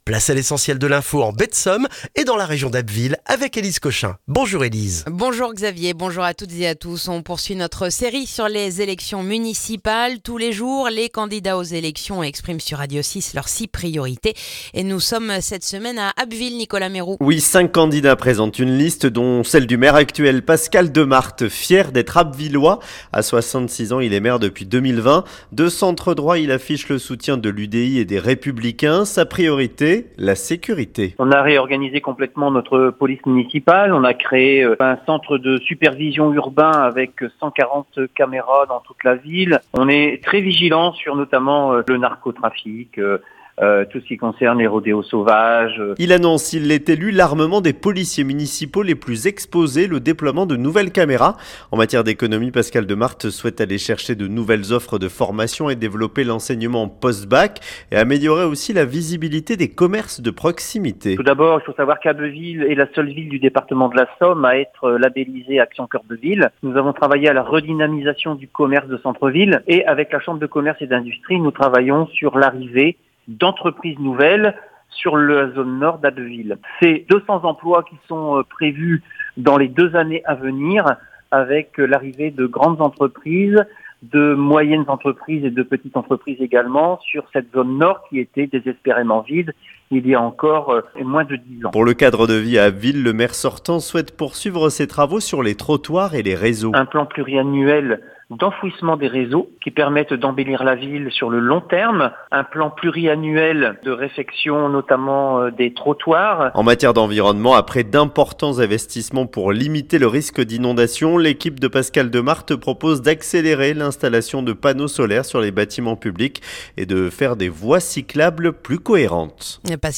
Le journal du mercredi 4 mars en Baie de Somme et dans la région d'Abbeville